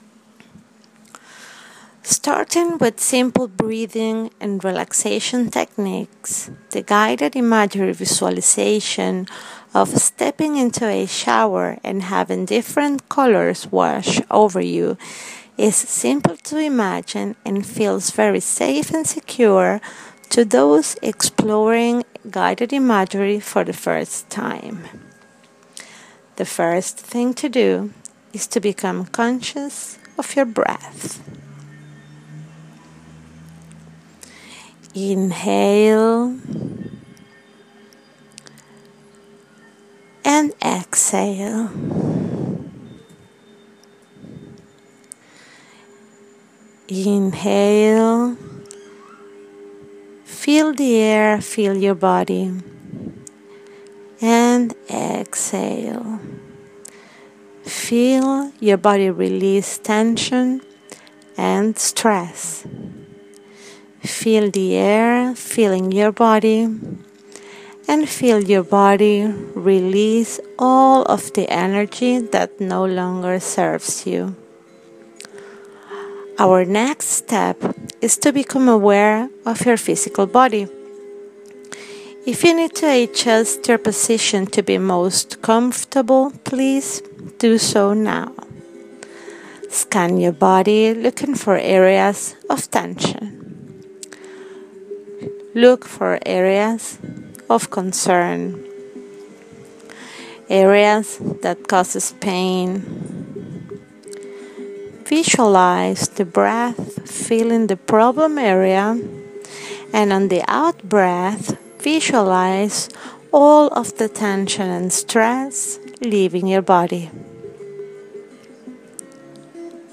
Guided colour imagery meditation for healing - Azulfit
Colour-Guided-imagery-Meditation.m4a